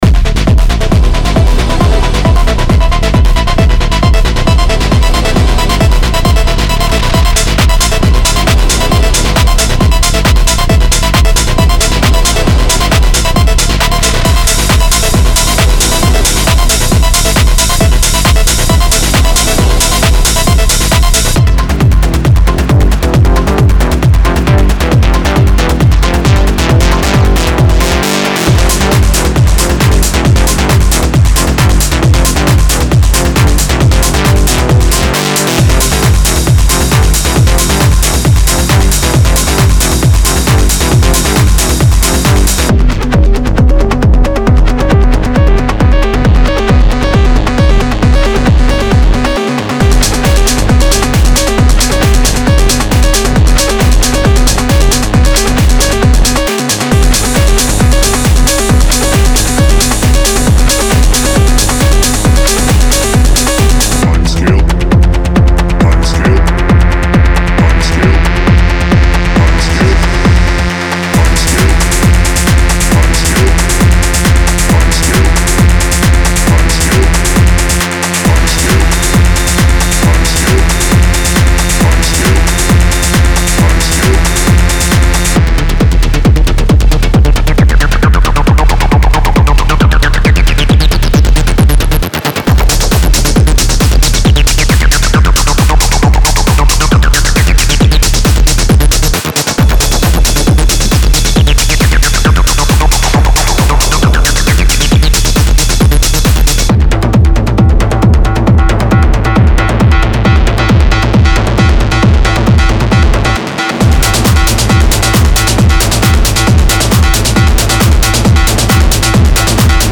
Raw Power for the Dancefloor